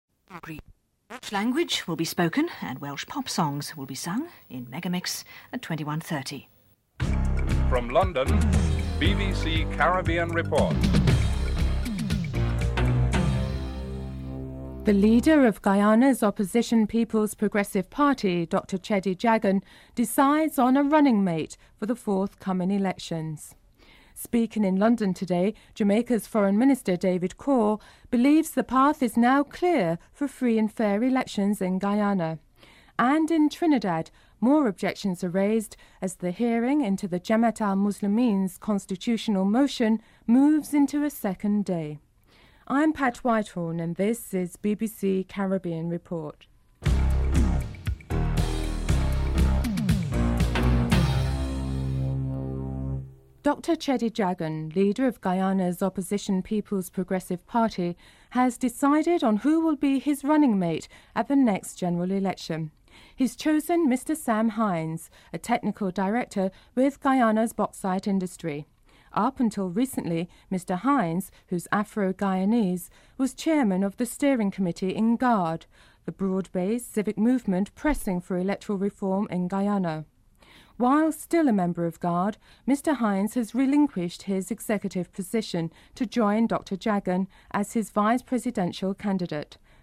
Unrelated audio at the beginning of the report.
1. Headlines (00:00-00:55)